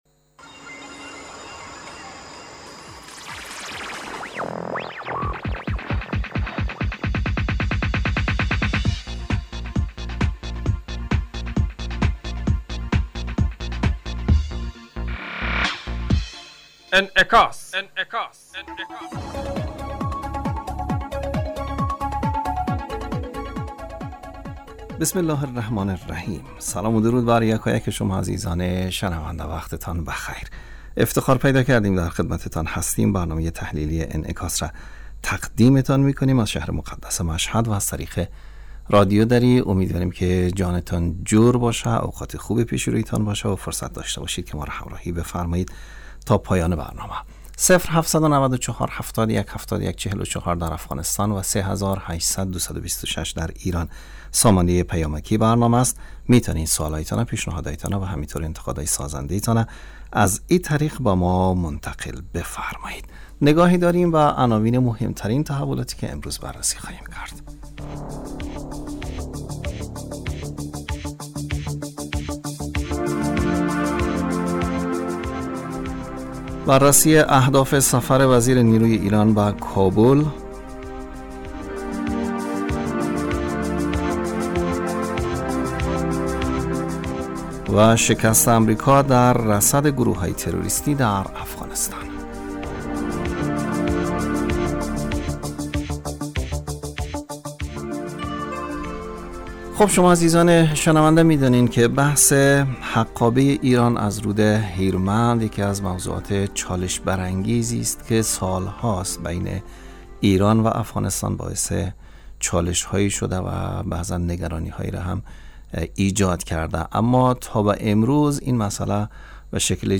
برنامه انعکاس به مدت 35 دقیقه هر روز در ساعت 18:50 بعد ظهر بصورت زنده پخش می شود.